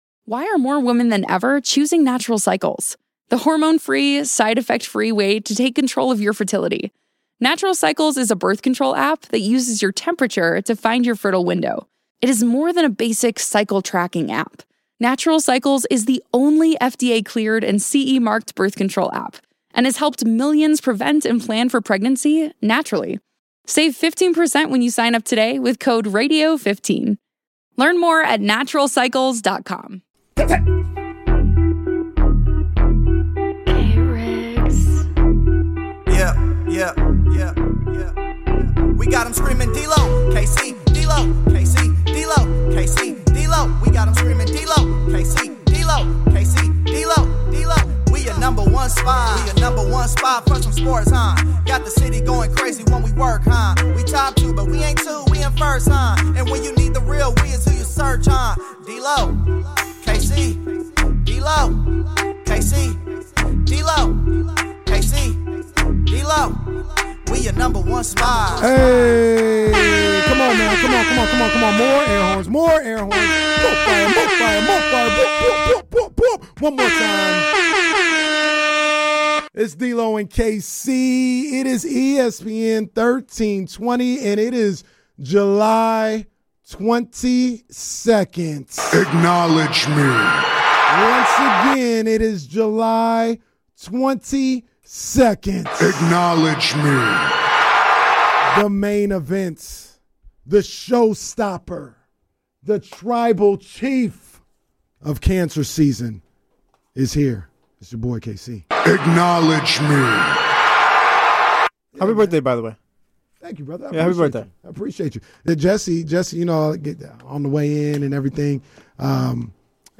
We are live from Rams training camp at Loyola Marymount University.